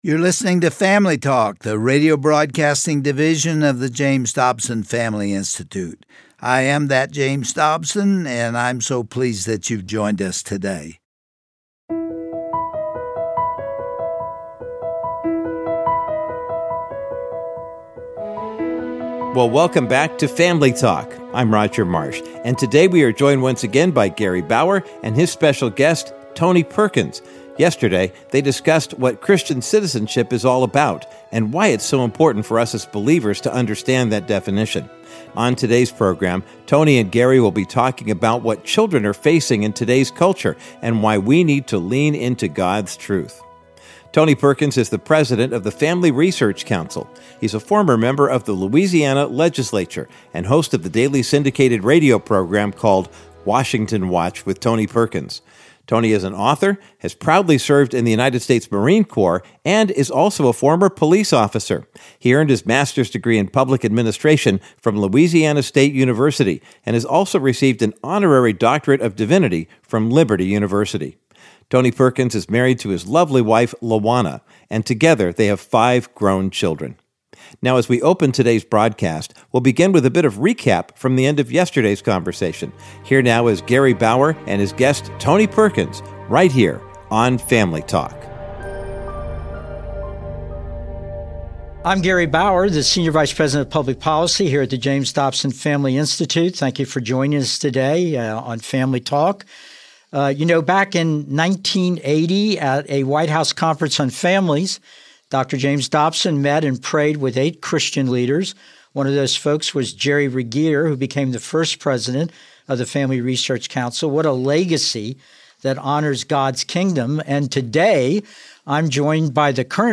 On today’s edition of Family Talk, Gary Bauer concludes his critical discussion with Tony Perkins, president of the Family Research Council about our responsibilities as Christian citizens. Tony passionately shares that even though we are seeing evil becoming more prevalent, we are also experiencing God’s light becoming brighter.